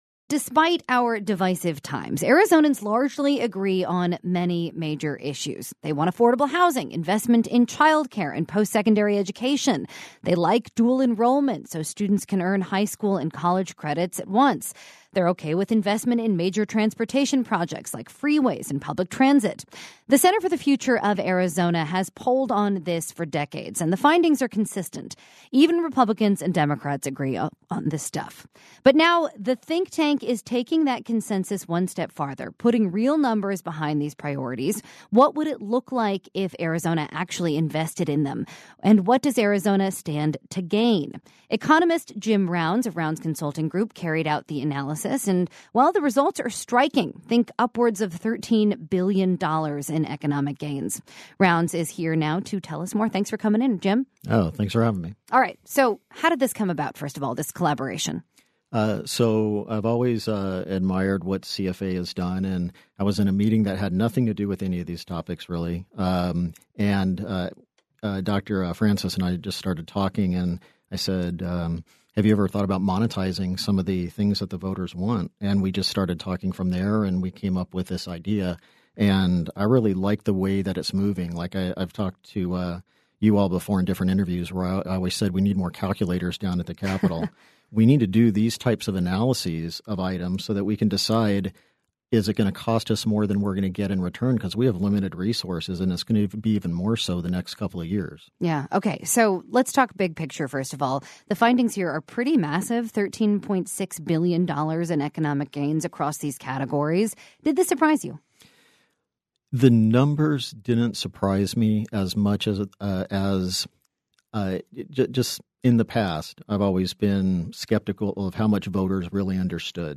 Full conversation